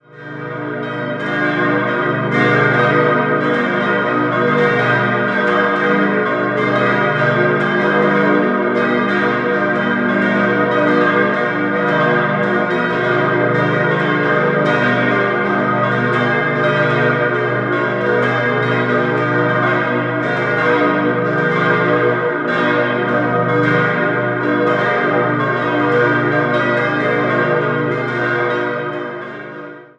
Zuvor nutzte man die Schlosskapelle des Schlosses Freudenhain, welche sich aber schon bald als zu klein erwies. 6-stimmiges Geläute: h°-dis'-fis'-gis'-h'-cis'' Die fünf größeren Glocken wurden 1966, die kleinste 2006 von Rudolf Perner in Passau gegossen.